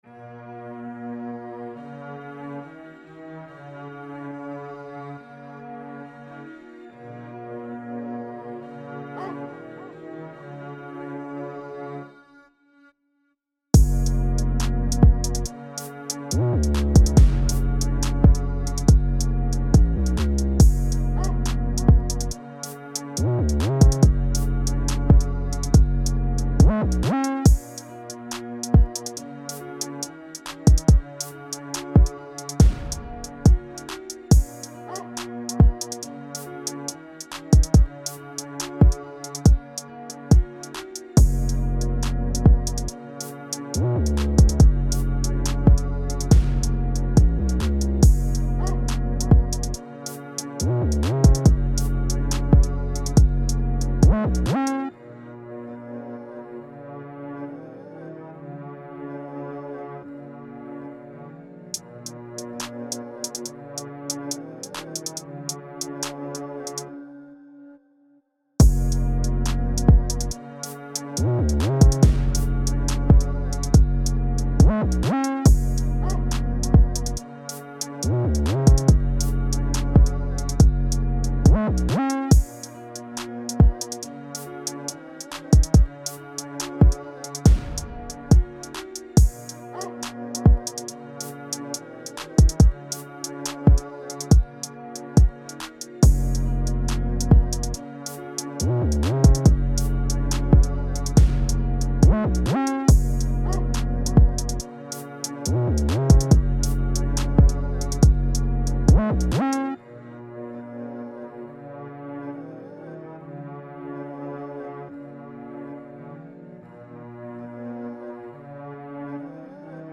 Cold, Energetic, Sexy
Drum, Heavy Bass, Piano, Strings